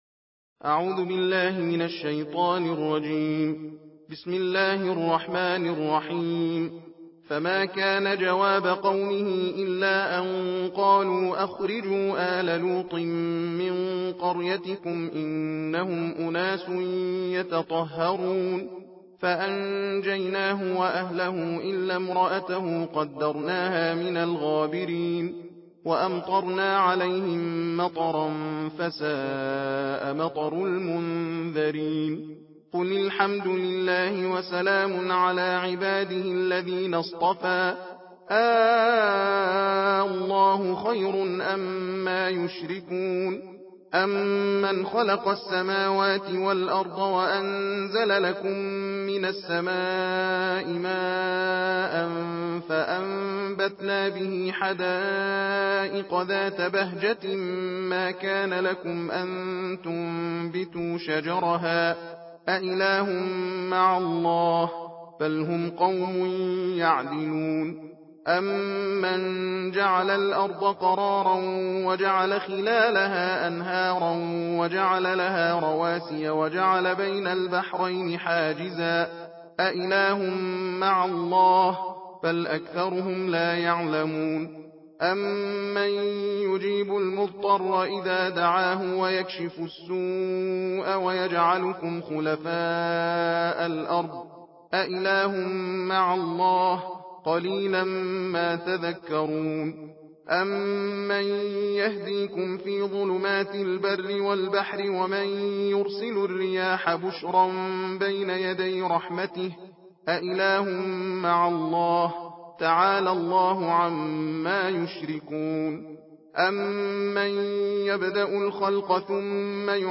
صوت/ تندخوانی جزء بیستم قرآن کریم